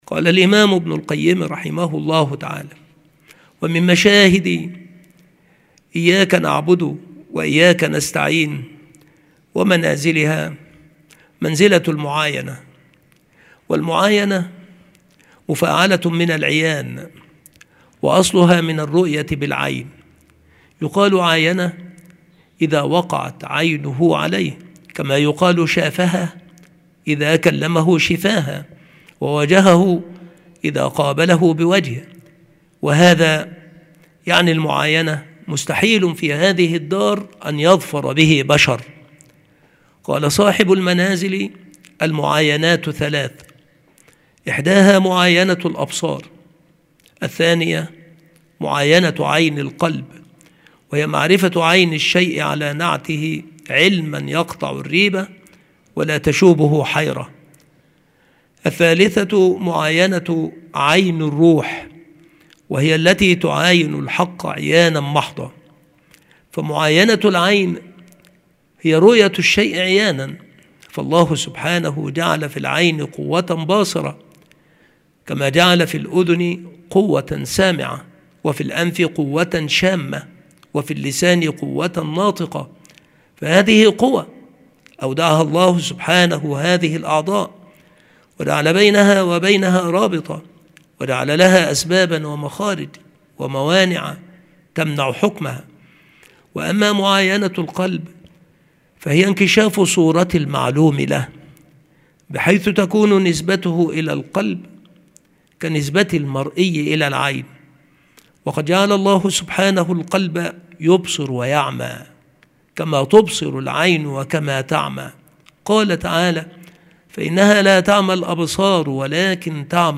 مكان إلقاء هذه المحاضرة المكتبة - سبك الأحد - أشمون - محافظة المنوفية - مصر عناصر المحاضرة : منزلة المعاينة. أنواع المعاينة وأقسامها. شواهد السائر إلى الله.